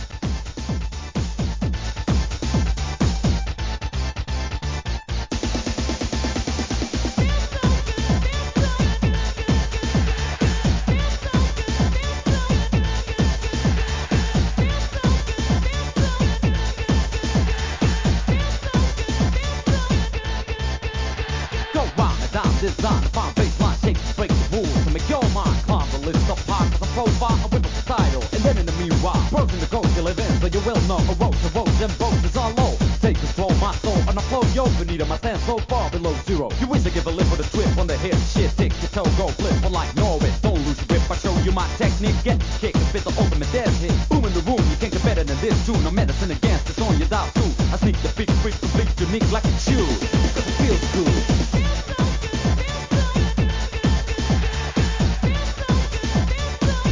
HIP HOP/R&B
Euro BeatでのRAP!!